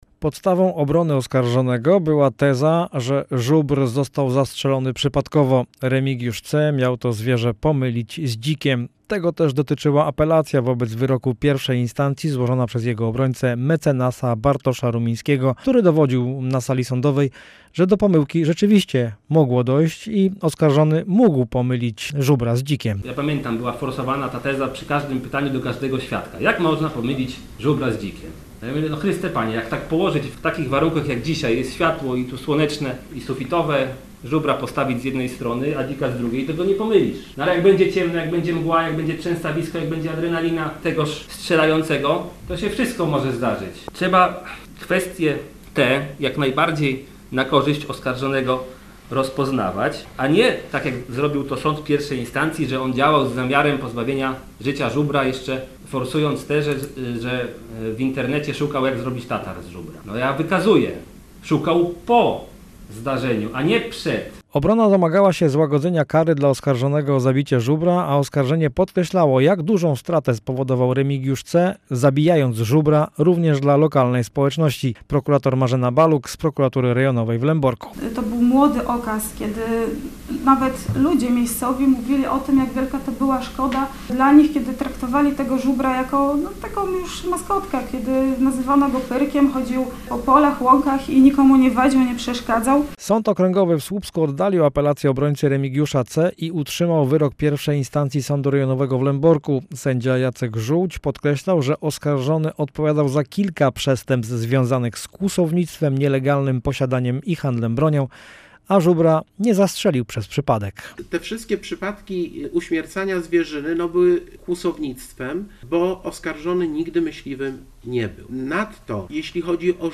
– Obrońca w apelacji powiada, przypadkowo został zastrzelony, ponieważ oskarżony pomylił go z dzikiem – uzasadniał wyrok sędzia Jacek Żółć.